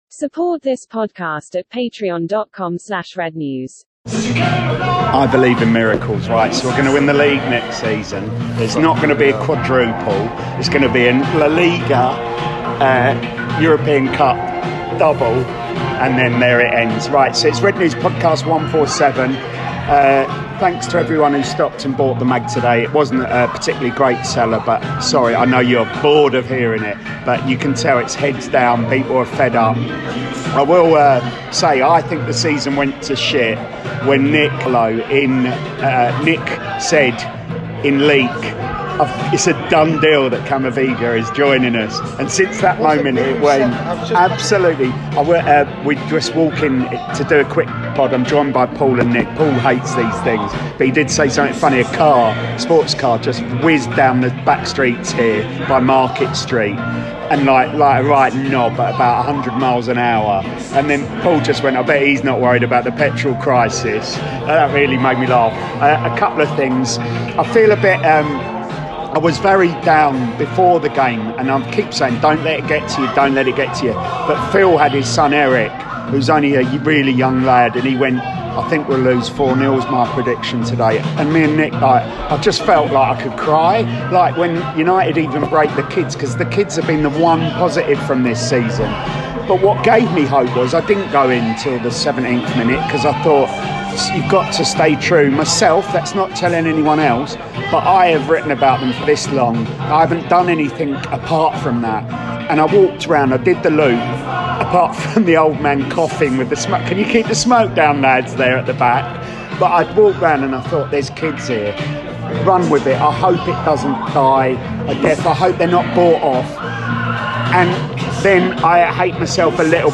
The independent, satirical Manchester United supporters' fanzine - for adults only, contains expletives, talks MUFC, or not at times, as we go over the protests, the Chelsea game and the usual tangents.